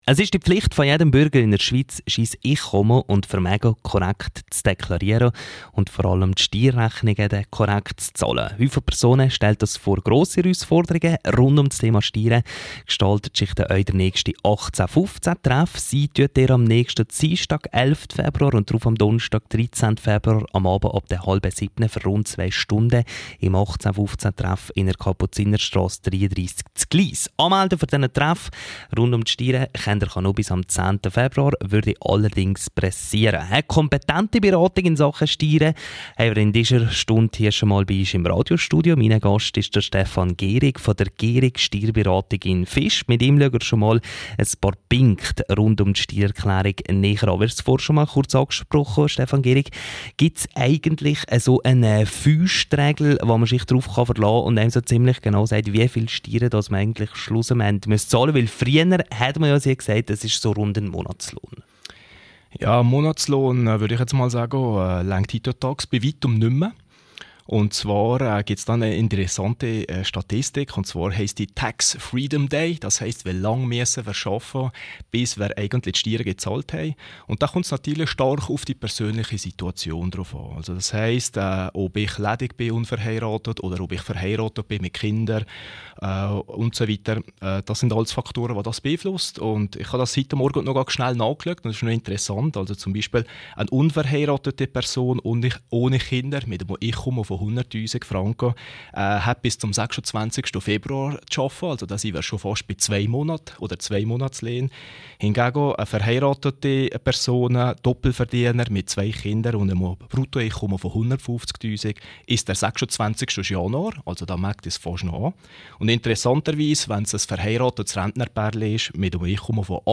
Ausschreibung Walliser Bote Rund ums Thema Steuererklärung durfte ich heute Morgen dem Radio Rottu (rro) Red und Antwort stehen.